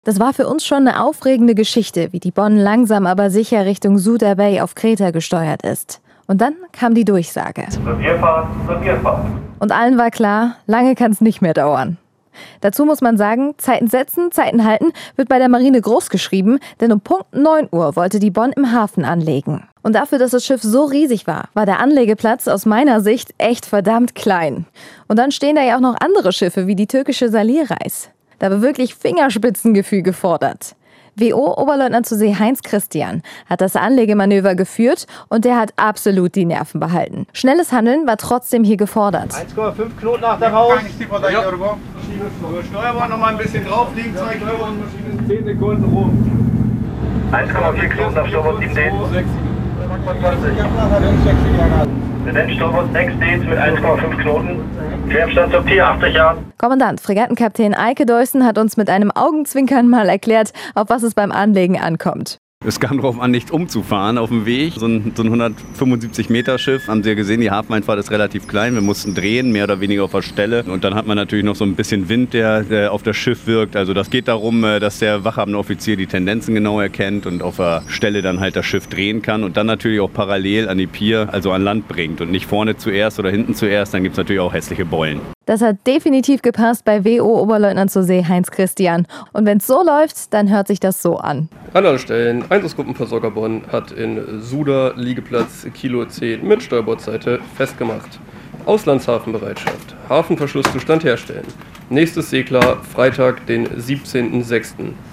Unser Korrespondenten-Team nimmt uns noch einmal mit auf die Brücke des EGVEinsatzgruppenversorger Bonn.